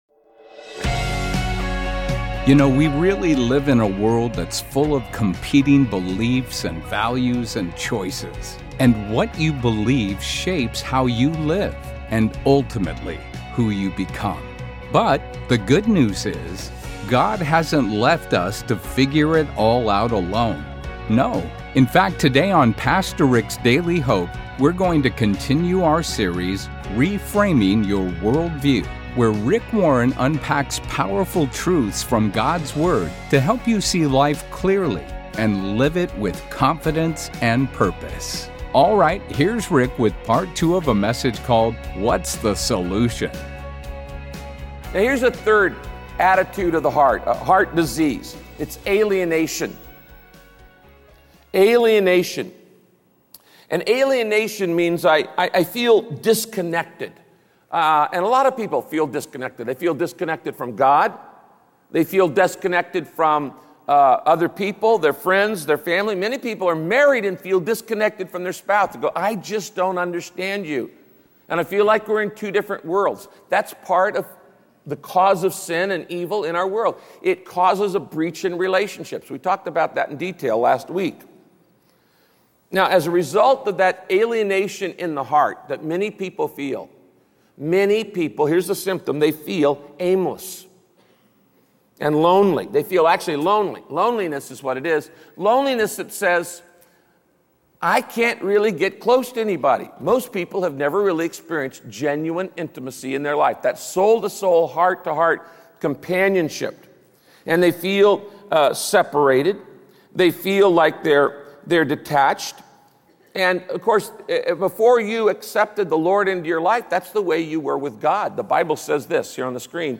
One of the biggest problems in the world is heart disease—but not the physical kind. In this message, Pastor Rick walks through the common spiritual heart diseases and how to spot the symptoms so you can bring them to Jesus, the Great Healer.